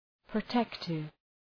Προφορά
{prə’tektıv}